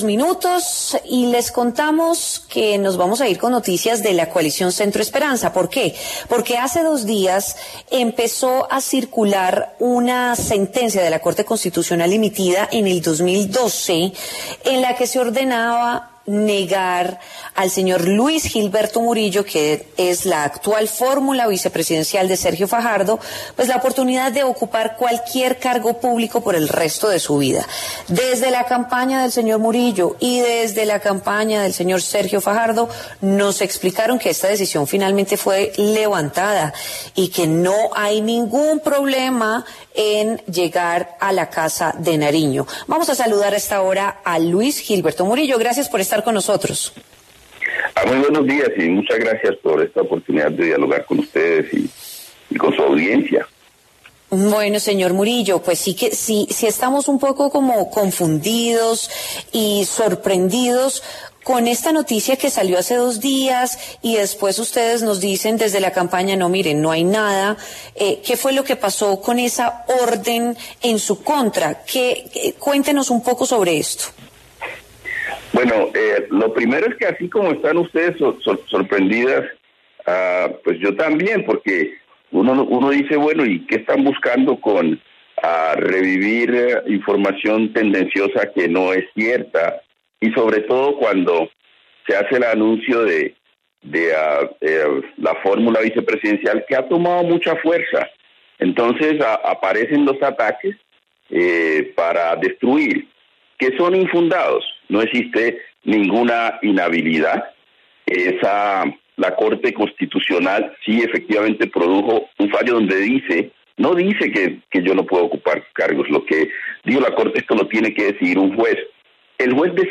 En diálogo con W Fin de Semana, Murillo se declaró sorprendido por esta información al preguntarse qué es lo que están buscando sus detractores “con revivir información tendenciosa que no es cierta, sobre todo cuando se hace el anuncio de la fórmula vicepresidencial”.